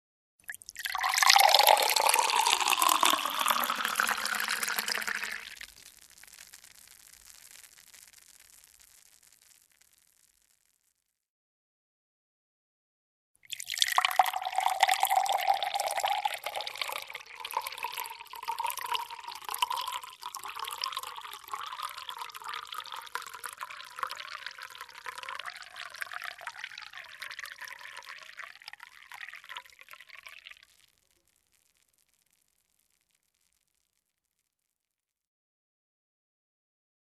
В коллекции представлены как натуральные записи, так и студийные варианты.
1. Шум сока, льющегося в стакан n2.